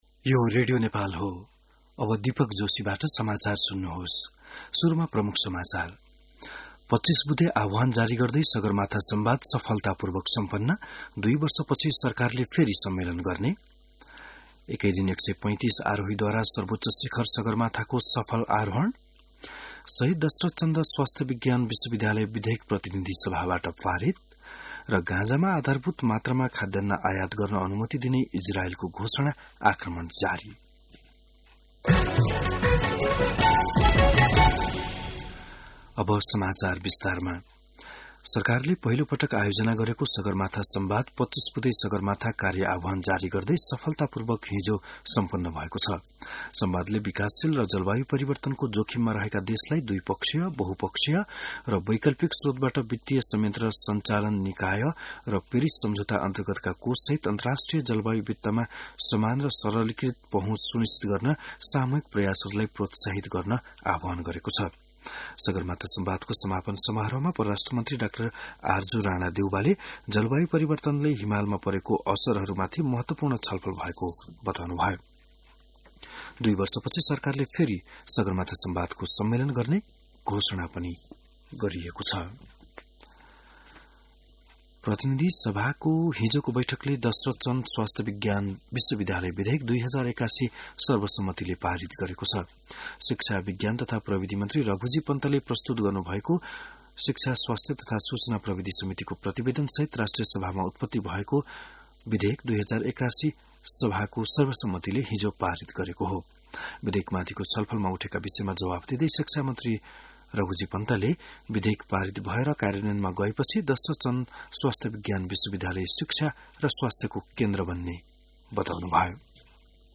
बिहान ९ बजेको नेपाली समाचार : ५ जेठ , २०८२